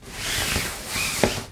Cloth Slide and Flap Sound
household
Cloth Slide and Flap